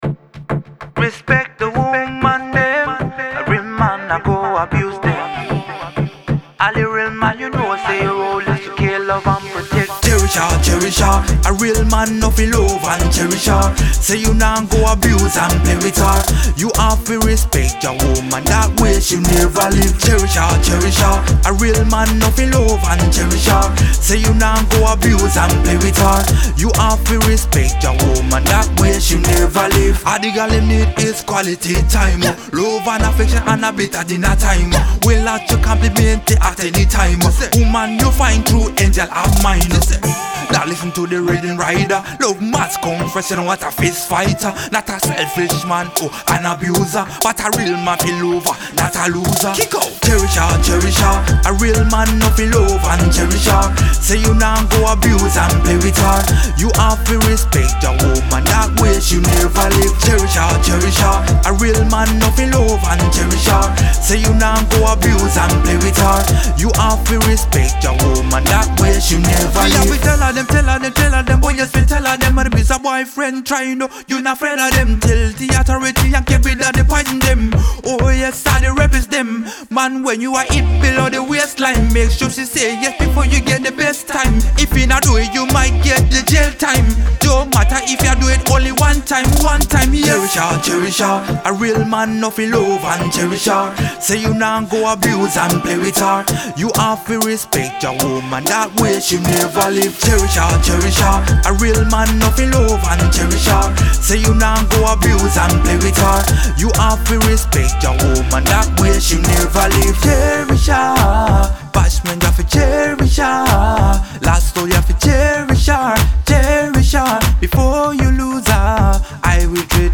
Genre: Reggae Dancehall
singjay